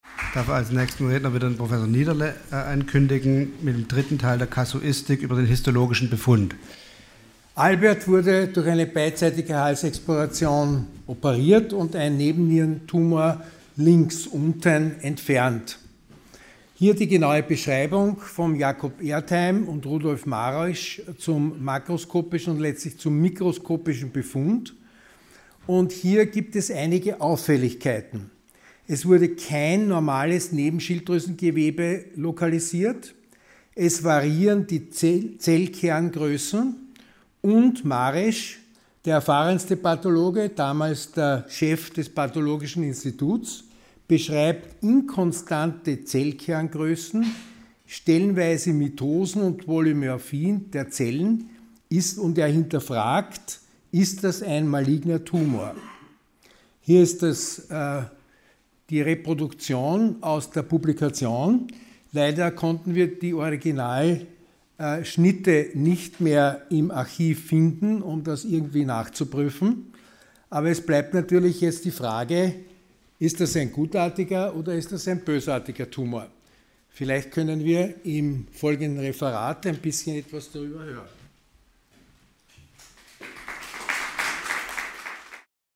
Sie haben den Vortrag noch nicht angesehen oder den Test negativ beendet.
Hybridveranstaltung